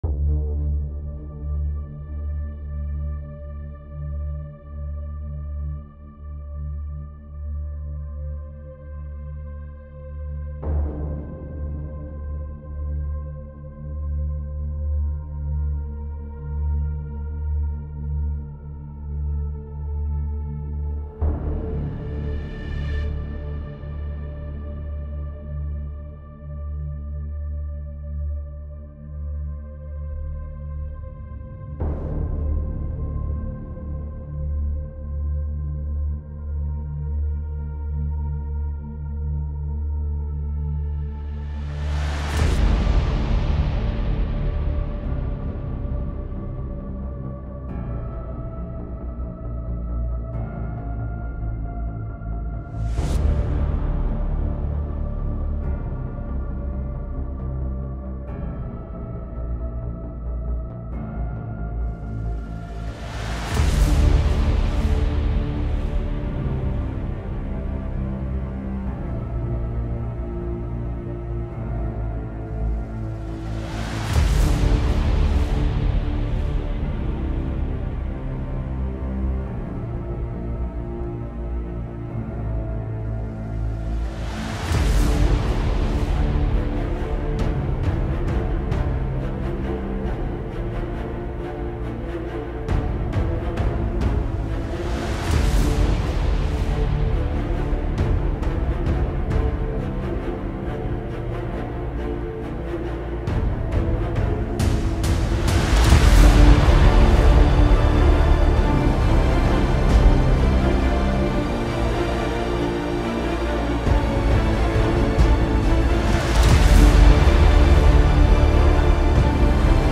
dark-motivational-music-rise.mp3
KDDoNcwakD7_dark-motivational-music-rise.mp3